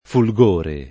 fulgore [ ful g1 re ]